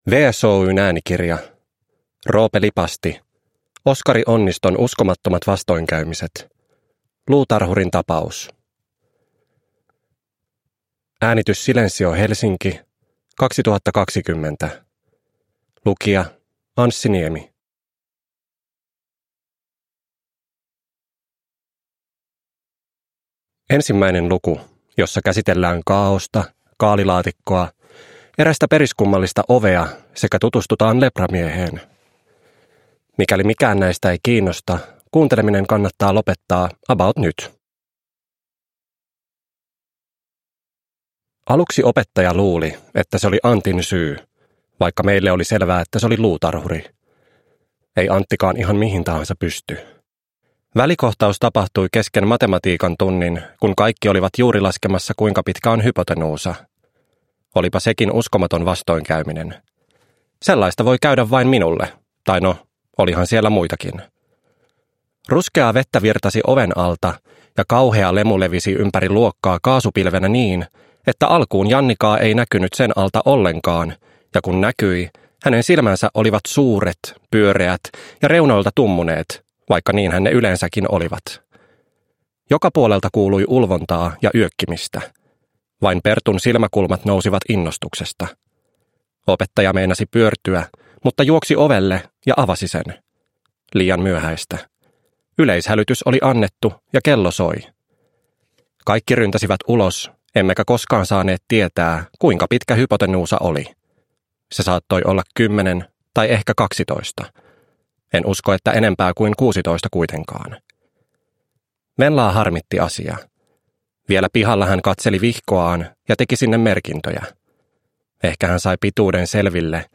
Luutarhurin tapaus – Ljudbok